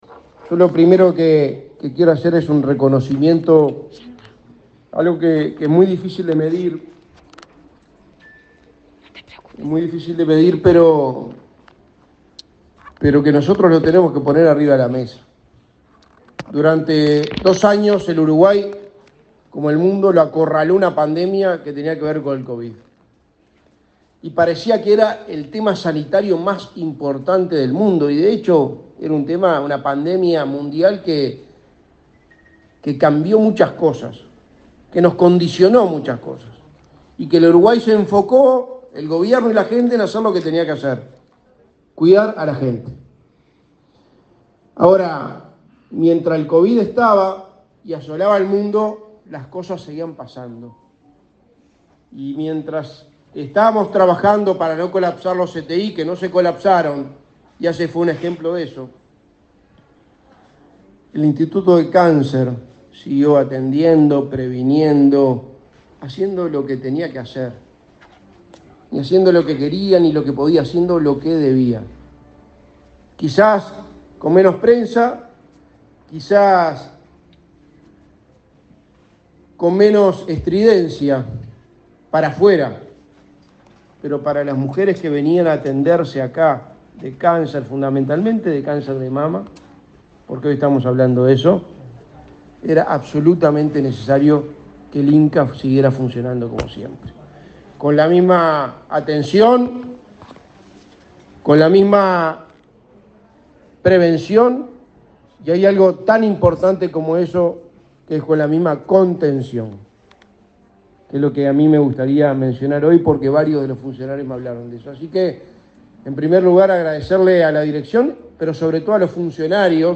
Palabras del secretario de Presidencia, Álvaro Delgado
La Administración de los Servicios de Salud del Estado (ASSE) inauguró este martes 25 el Área de Imagenología Mamaria y la Unidad de Estética